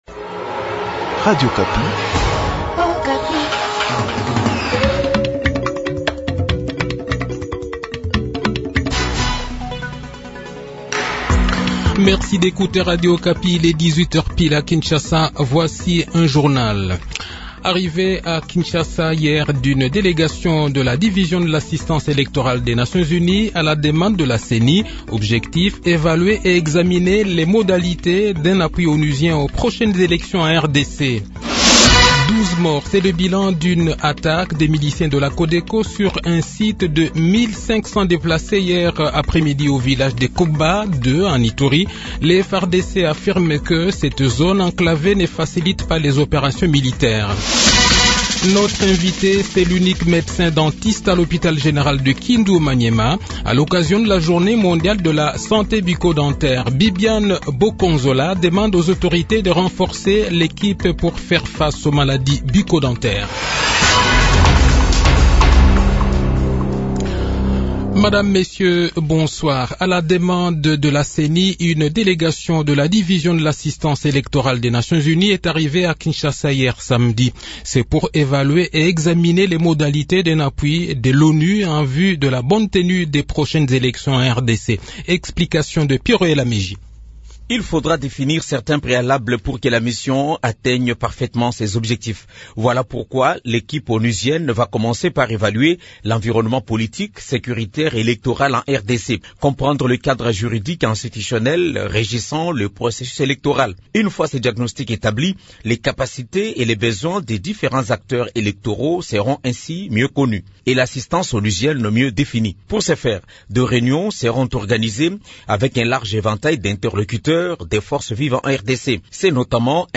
Le journal de 18 h, 20 mars 2022